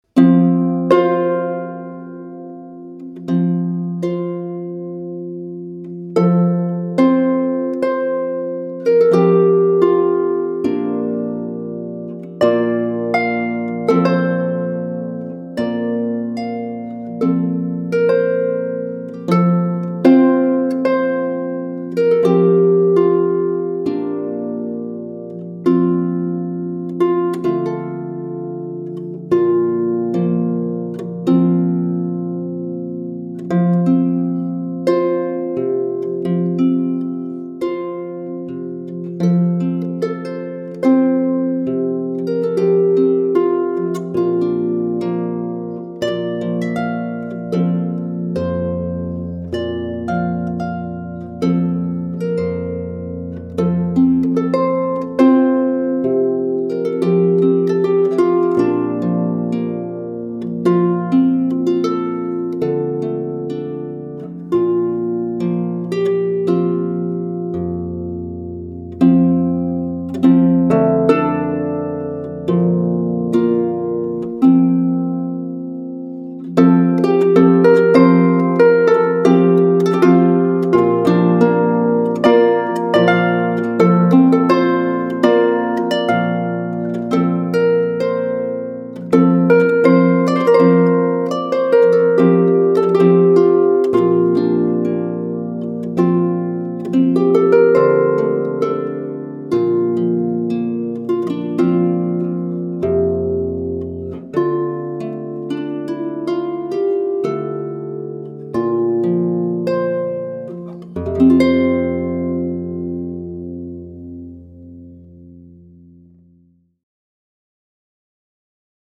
short solos